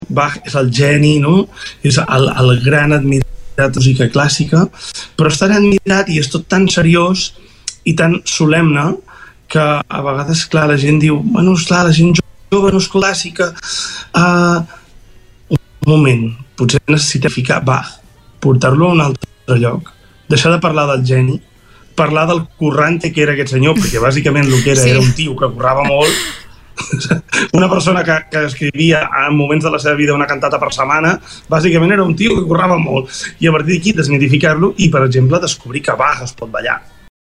Entrevistes SupermatíTorroella de Montgrí - l'Estartit